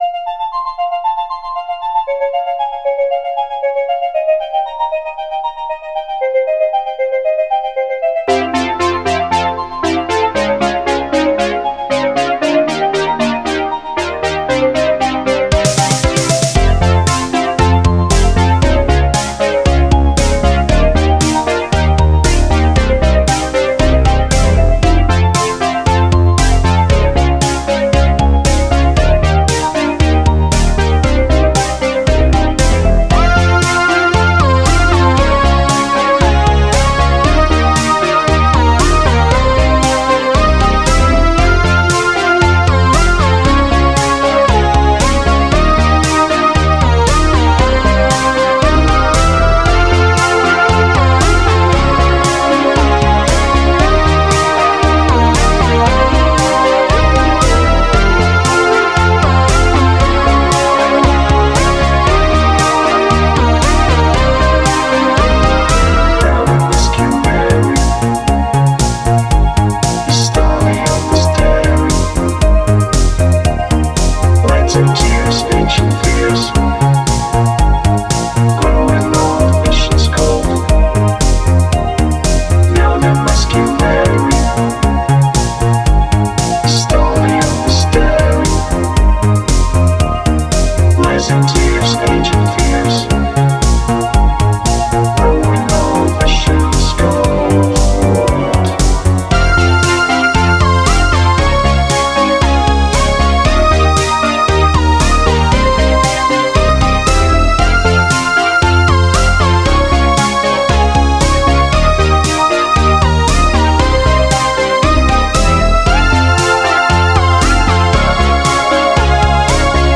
Жанр: Synthwave, Retrowave, Spacewave, Dreamwave, Electronic